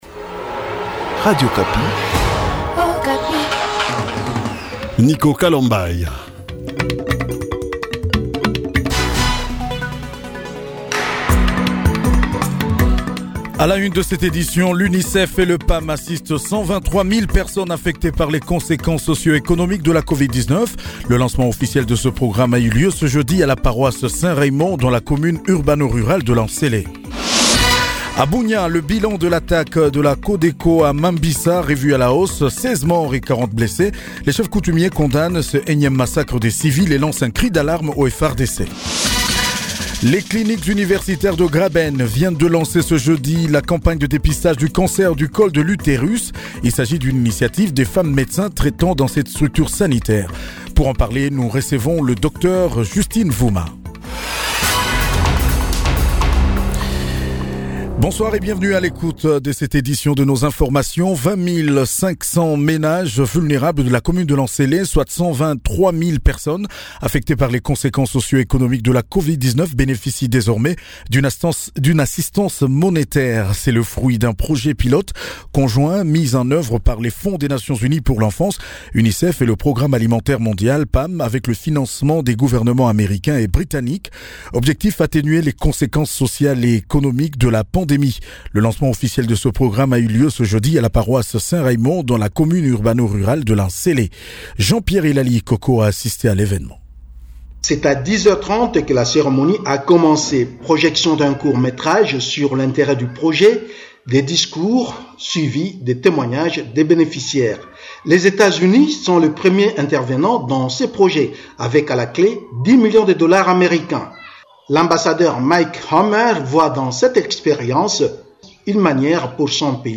JOURNAL SOIR DU JEUDI 18 MARS 2021